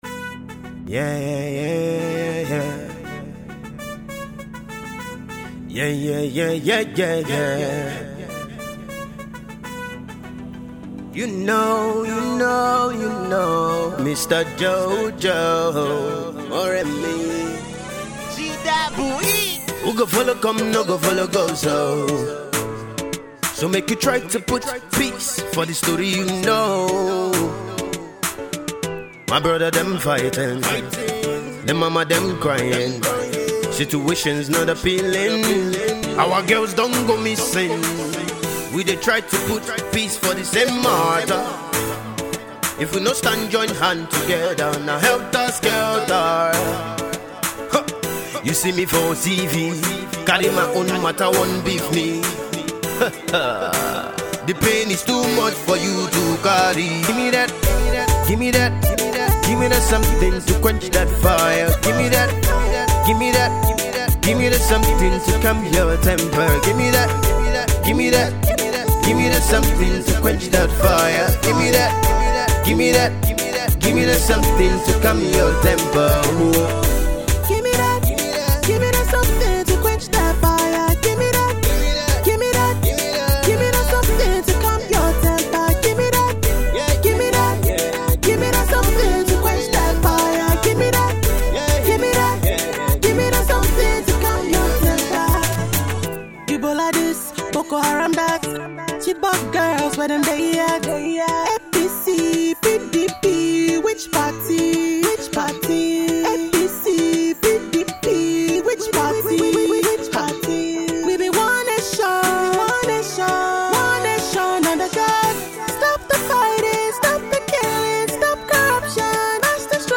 Conscious, Inspirational
the 17’yrs old emerging rapper
classically trained voice